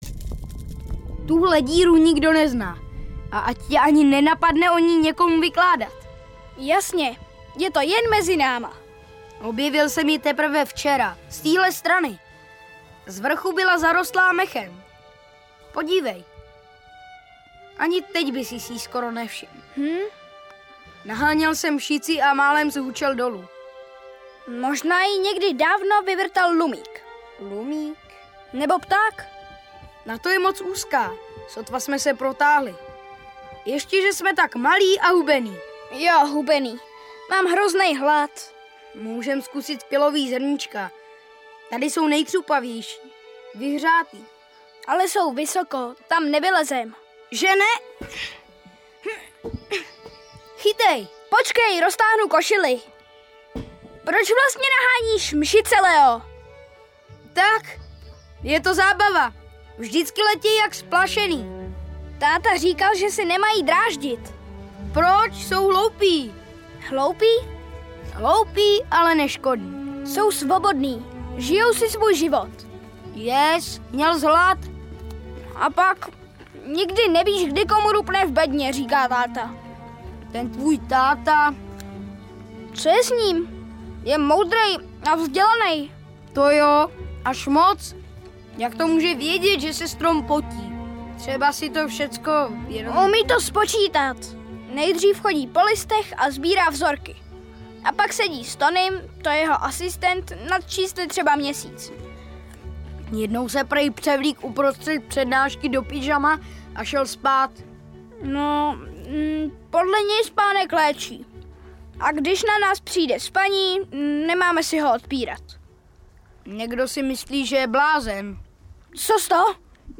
Tobiáš Lolness audiokniha
Ukázka z knihy